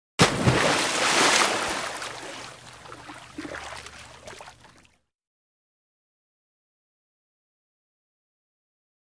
Large Splash Or Dive Into Water 3.